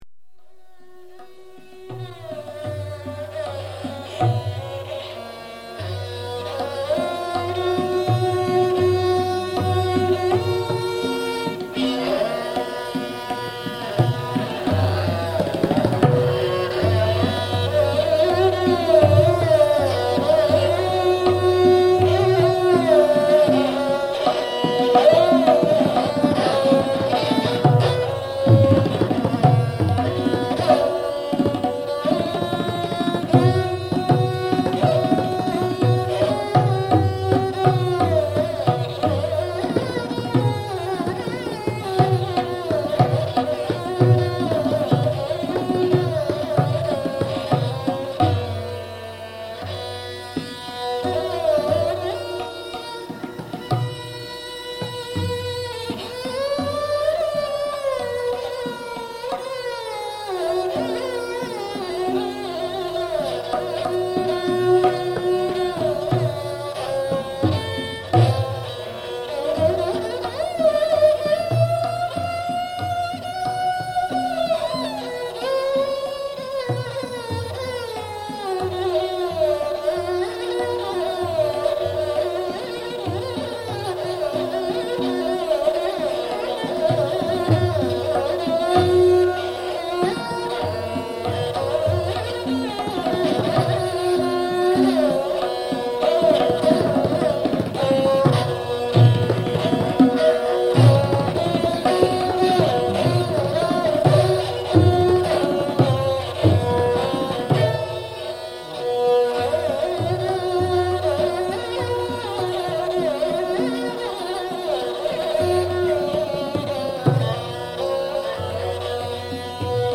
Stringed instrument and tabla